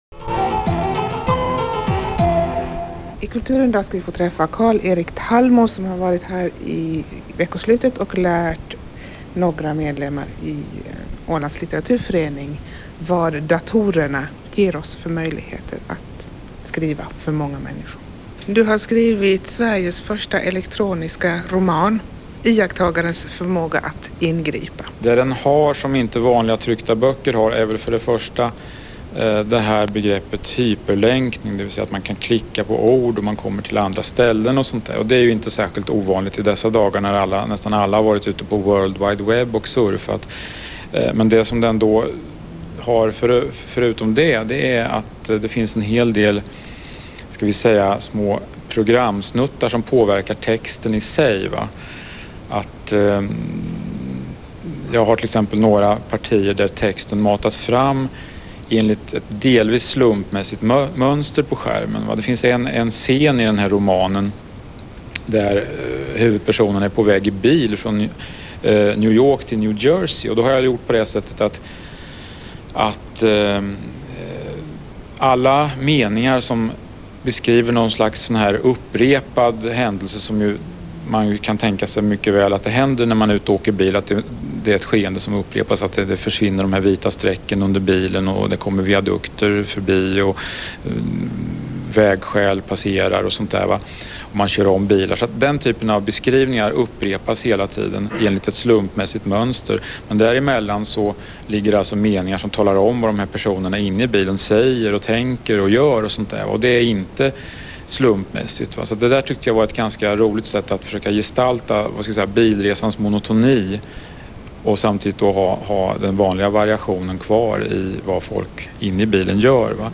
interview_mariehamn.mp3